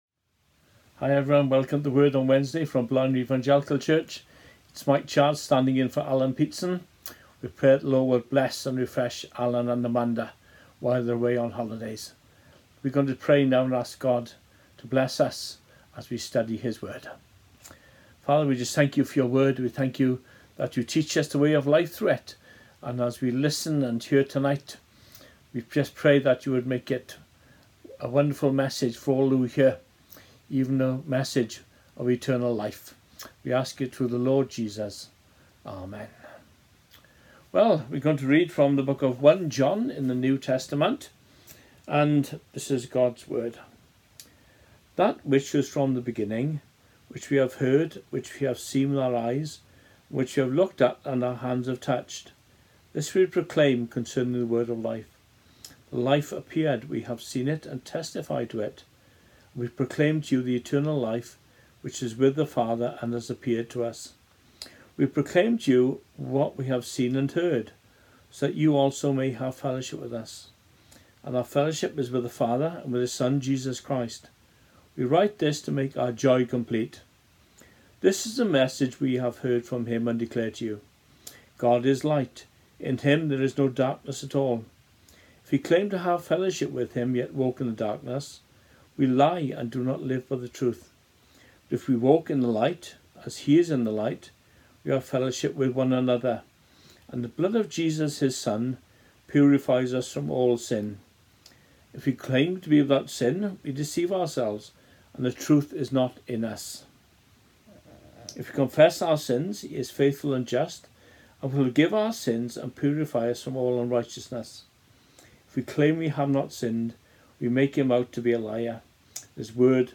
Series: Wednesday Devotional